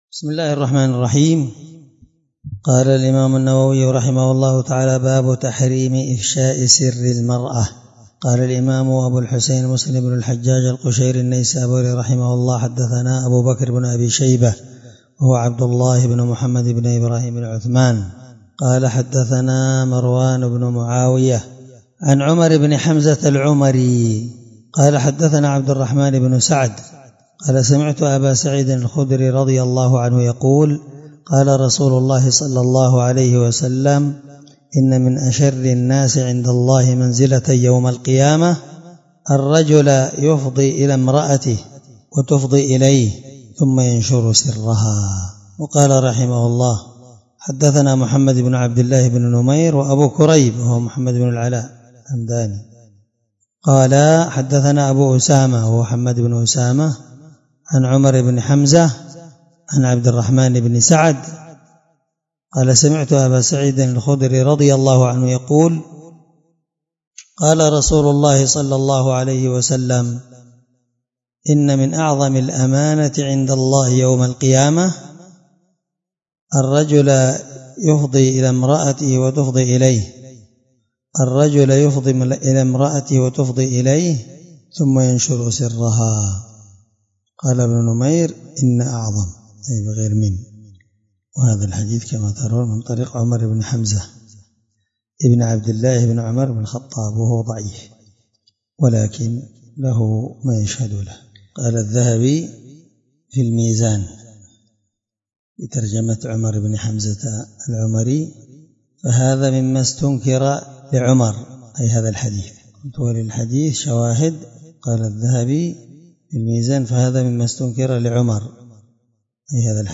الدرس30من شرح كتاب النكاح حديث رقم(1437) من صحيح مسلم